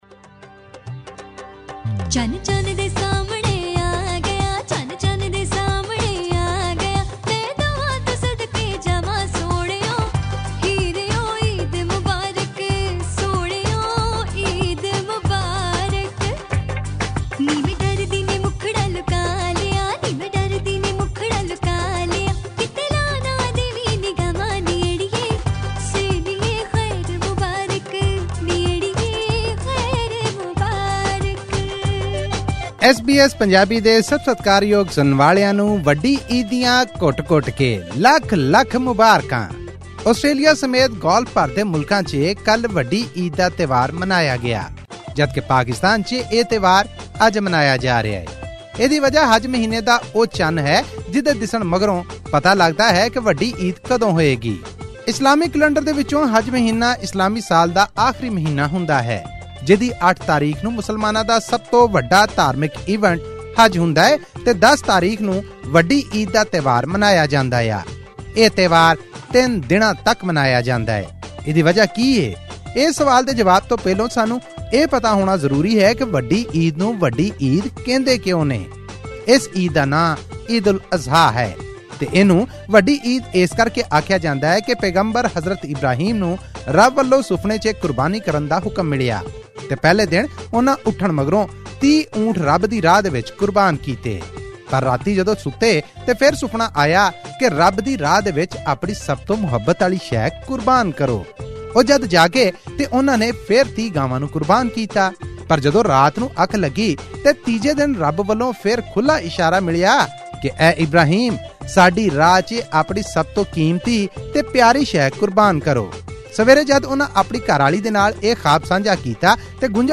audio report